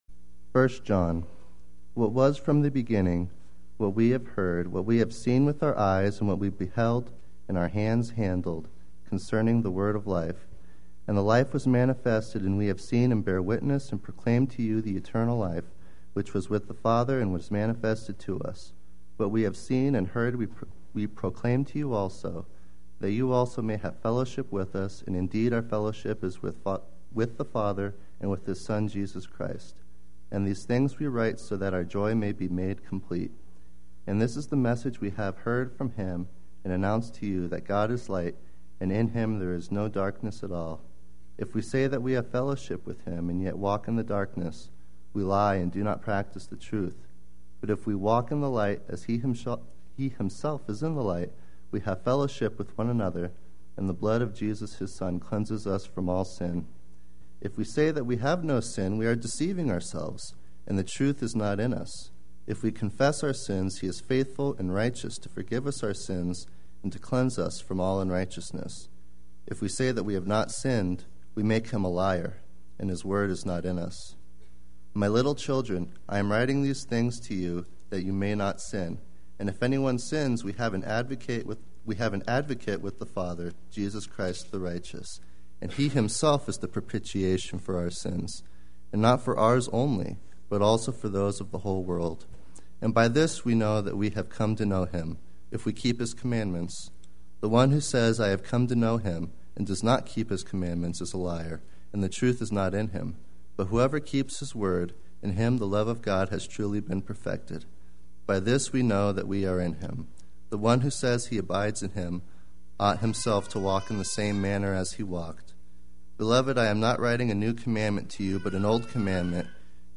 Play Sermon Get HCF Teaching Automatically.
A Biblical Perspective on Love and Acceptance Sunday Worship